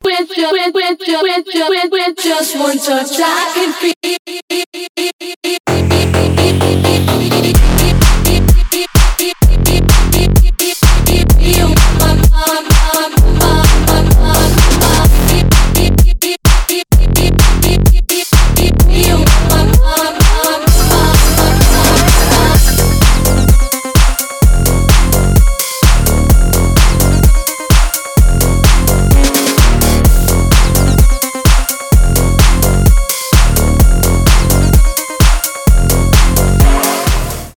Клубные рингтоны
melodic techno
техно house